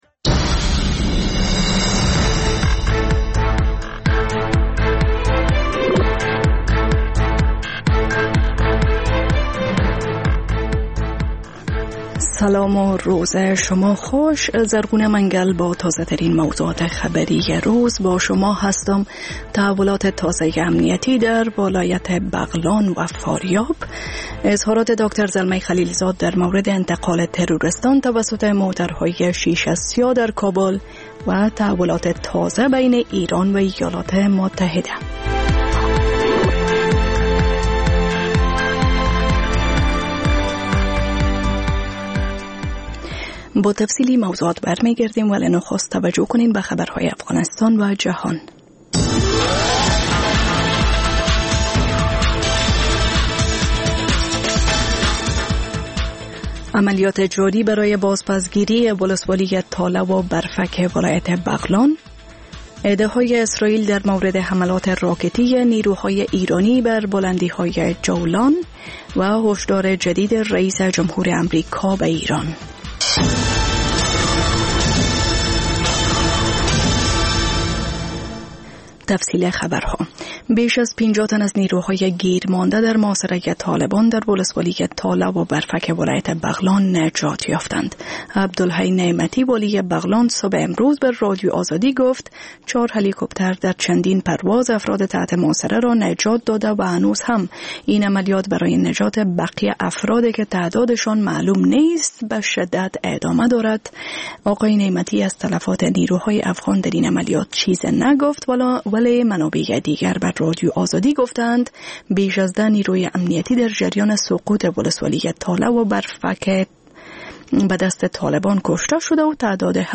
خبر ها و گزارش‌ها، سرود و سخن